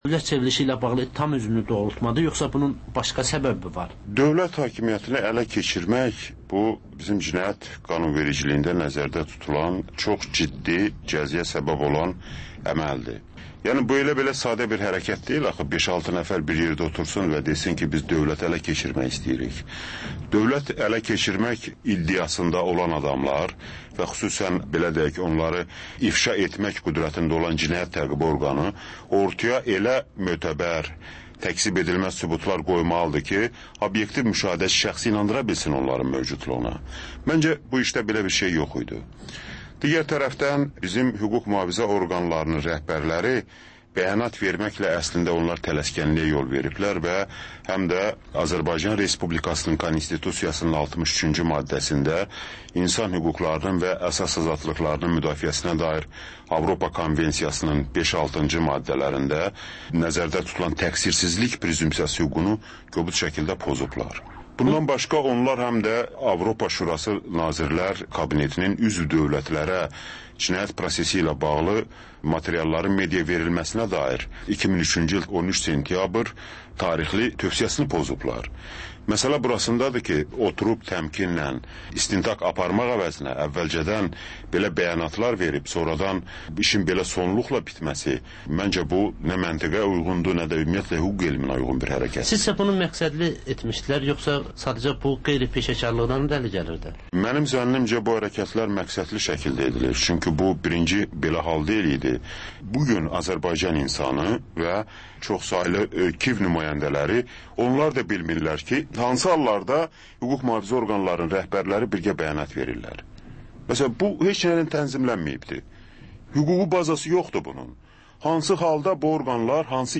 Azərbaycan, Gürcüstan ve Ermənistandan reportajlar, müzakirələr